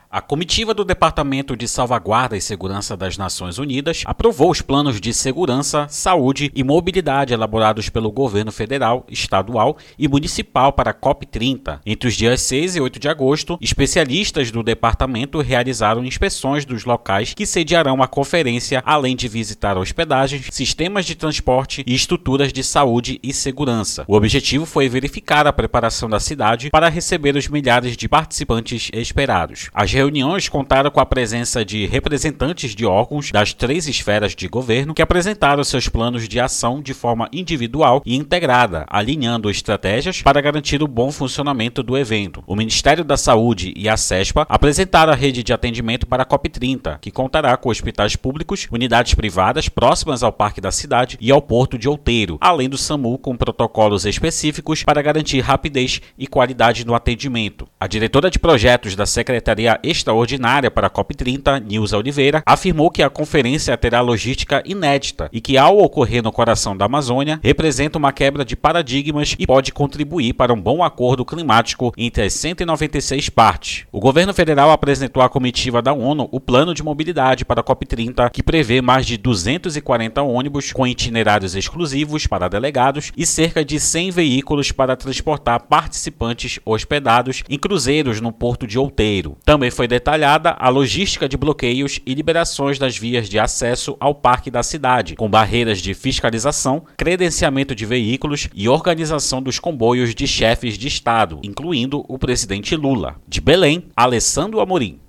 Além da reportagem